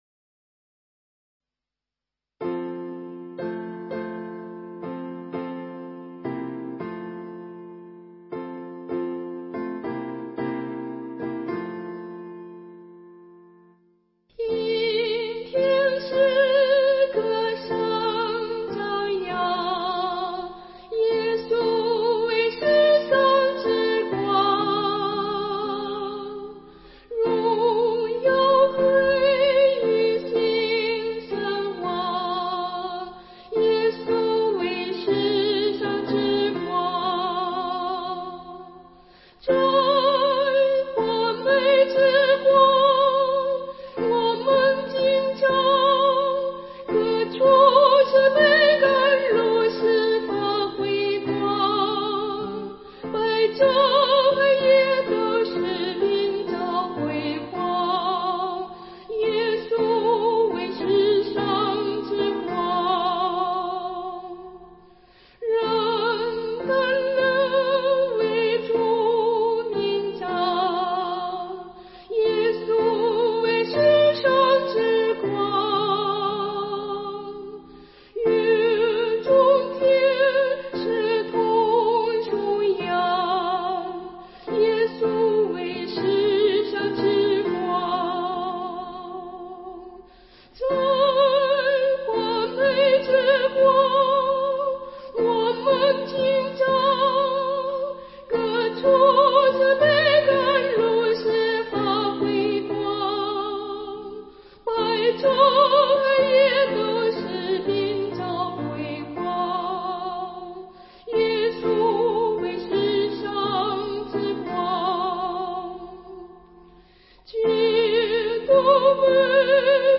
伴奏